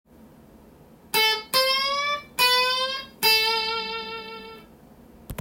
tab譜のkeyはAにしてみました。
Aメジャーペンタトニックスケール